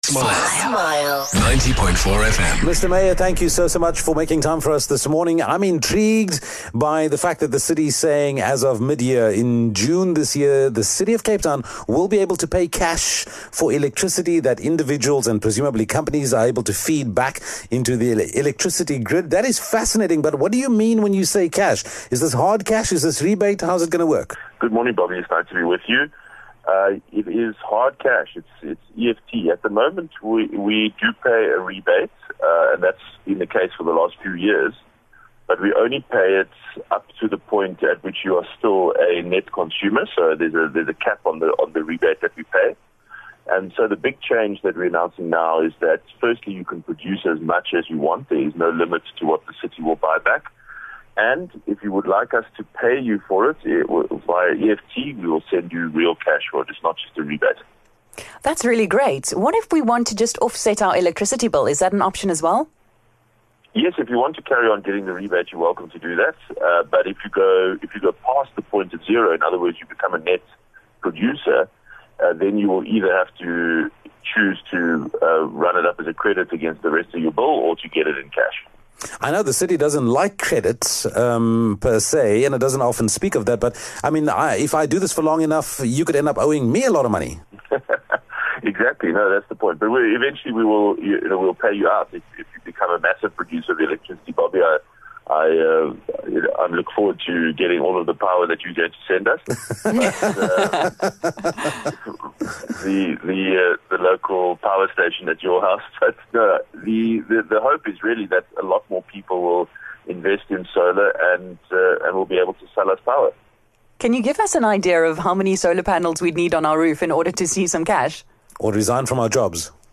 Cape Town can officially start paying people for the power they sell back to the city. Smile Breakfast spoke to mayor Geordin-Hill Lewis about the initiative and how residents will be able to make some extra cash if they invest in solar panels.